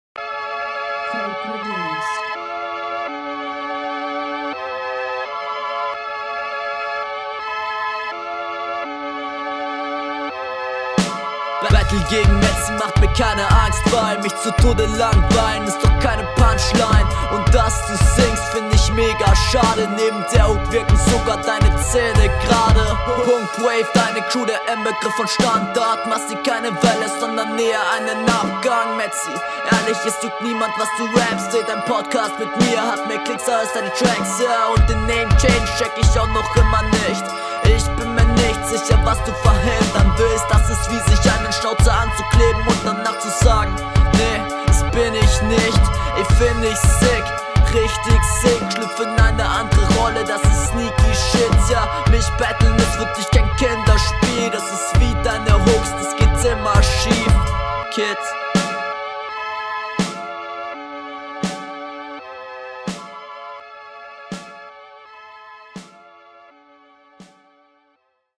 ➨ Takt getroffen.
Du kommst ziemlich cool auf dem Beat, schöner Stimmeinsatz und auch flowlich passt das cool …